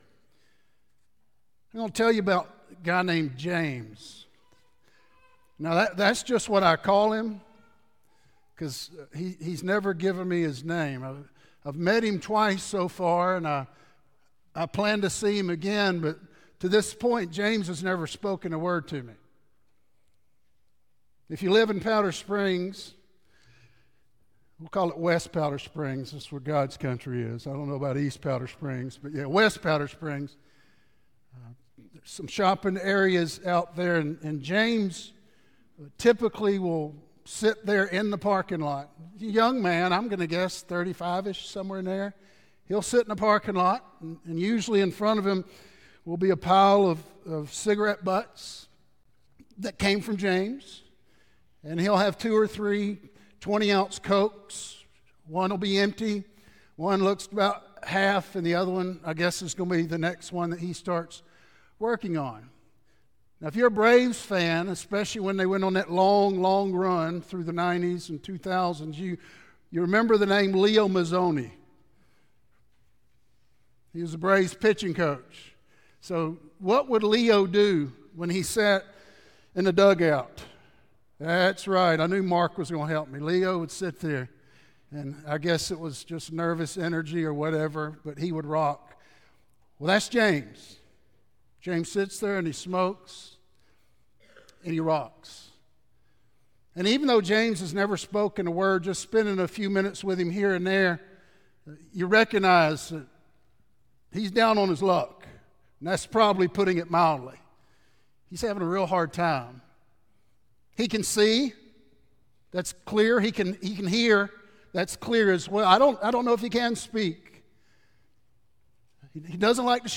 Sermons | West Metro Church of Christ